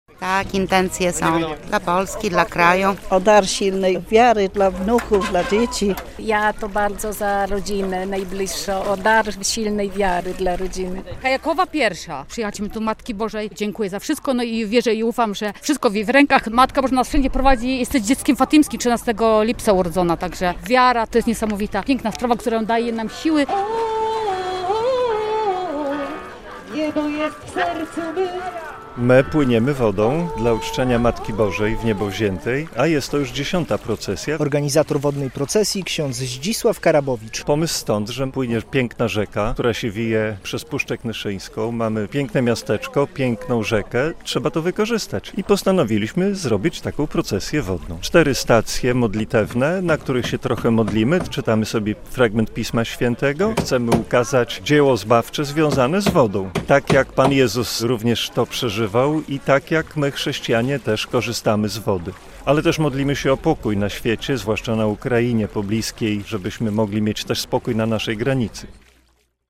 Procesja Wodna - relacja